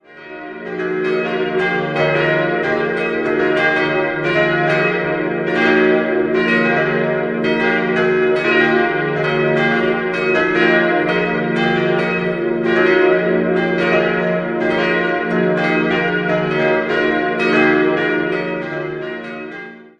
Die Pfarrkirche entstand um das Jahr 1400, wobei 1910/11 das Langhaus erweitert wurde. 4-stimmiges Geläut: es'-f'-g'-b' Die zweitkeinste Glocke wurde 1730 von B. Ernst in München gegossen, alle anderen entstanden 1952 bei Karl Czudnochowsky in Erding.